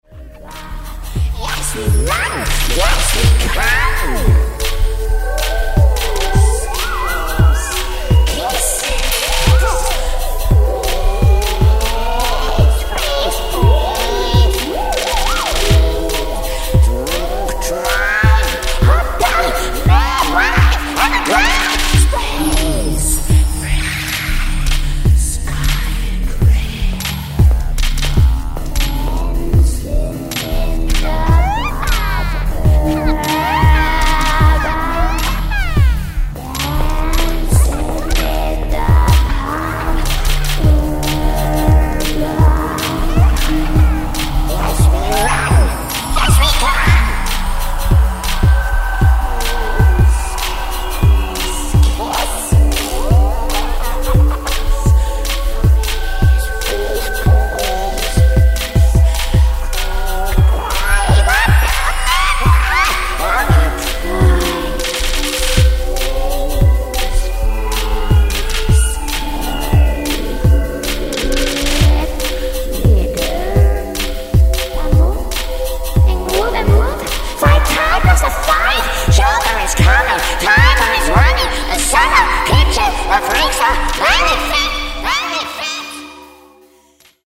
percussion, keyboard, bass
Percussion - Sounds and Dark Grooves with Clave Lyrics!